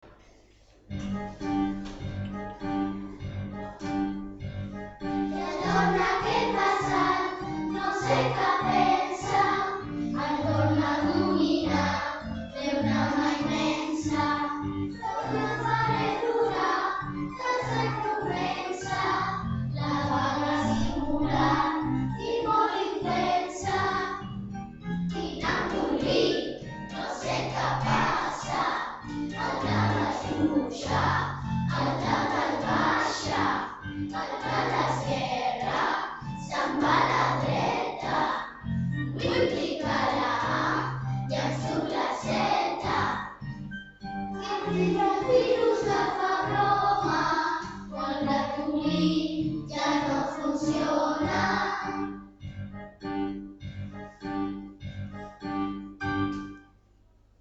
A continuació us posem totes les cançons de la cantata el Ratolí Electrònic text d’en Jesús Nieto i música d’en Ricard Gimeno que els alumnes de 4t hem enregistrat a l’escola .